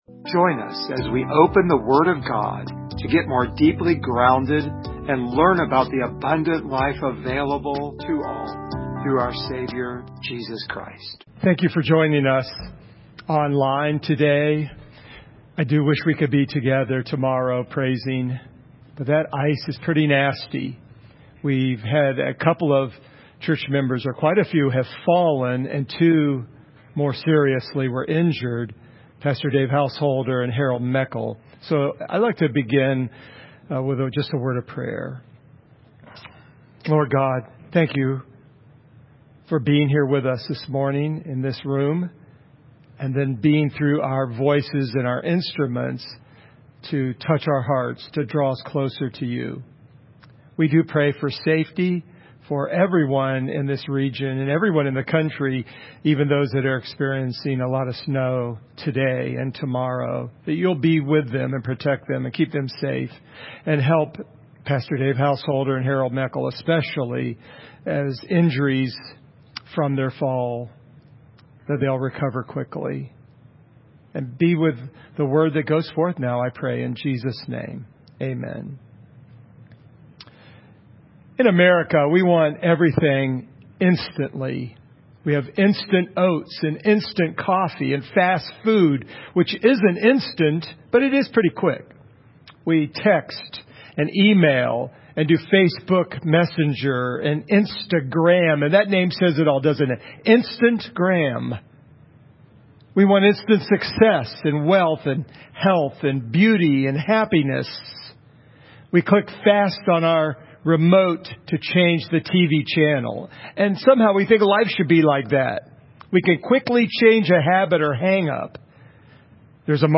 Service Type: Sunday Morning
Topics: Christian Life , Sanctification share this sermon « Healing Your Inner Hurts How Much Money Is Enough?